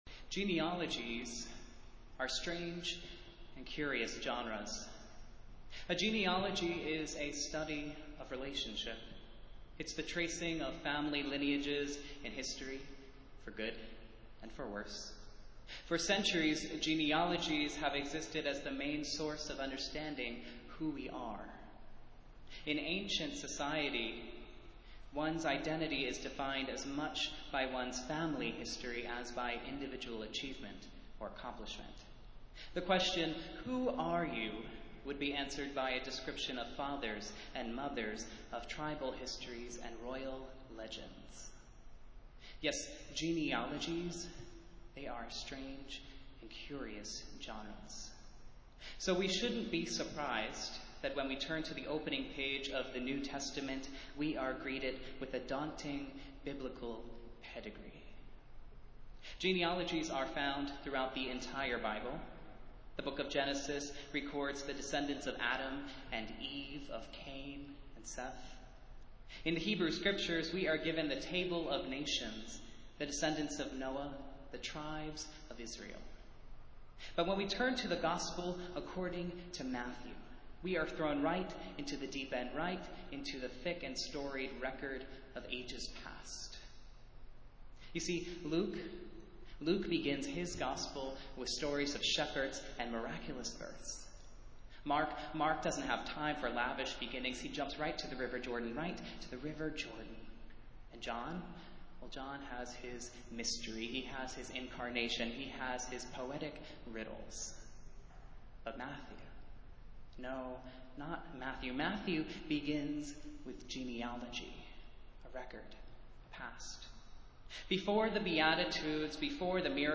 Festival Worship - Thirteenth Sunday after Pentecost